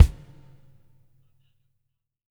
BW KICK 03-R.wav